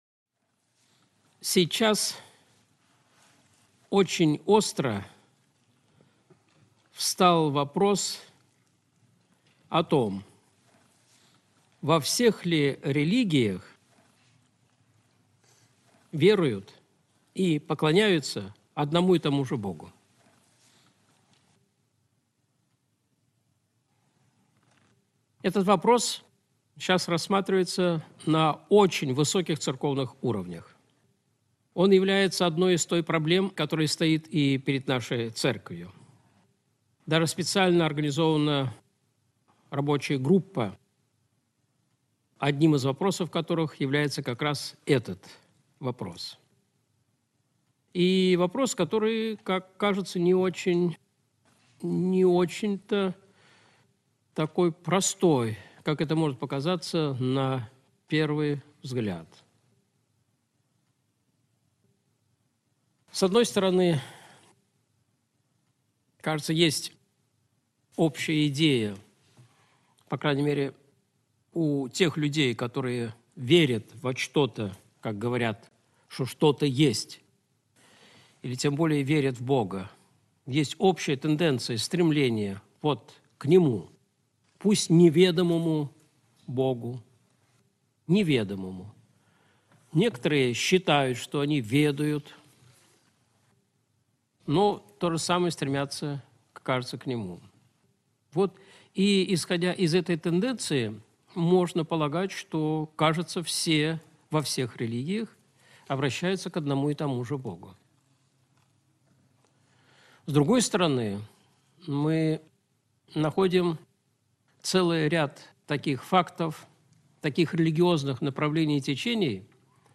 Видеолекции